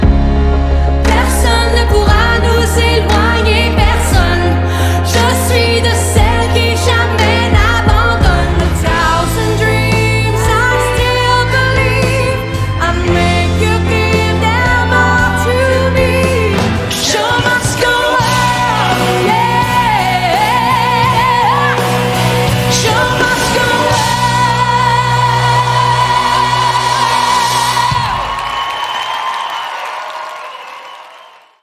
MONTAGE AUDIO